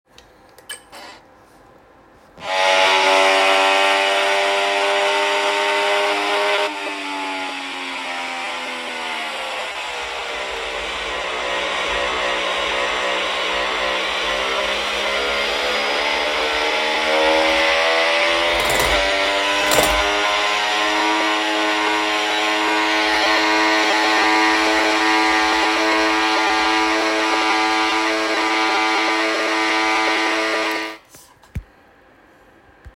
Schlechte Tonqualität bzw. Aussetzer am neuen System
Dieser "blecherne" Ton den ich meinte, irgendwie erinnert der mich entfernt an die Handy-Interferenzen die es mal gab wenn ein Anruf kam.
Ich habe ein Audio mit meinem Handy erstellt.